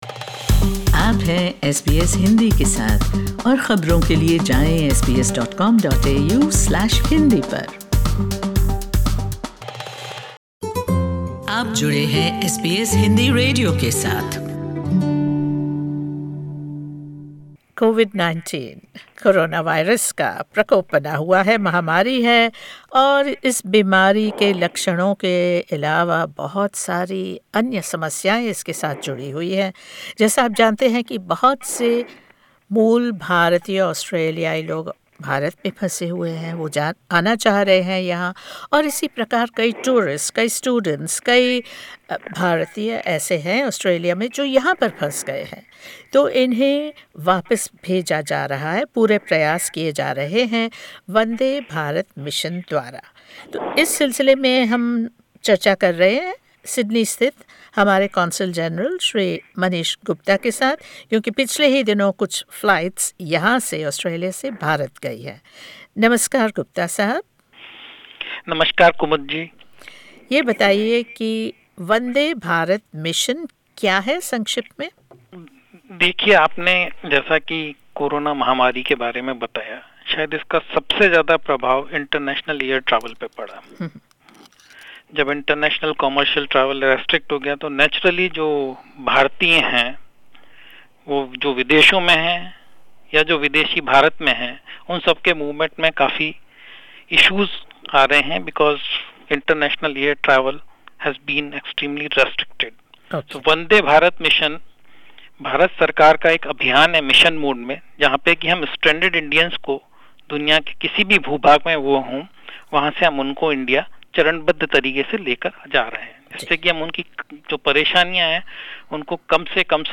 The Consul General of India in Sydney, Manish Gupta, explains the Vande Bharat Mission and elaborates on the repatriation program for Indians stranded in Australia.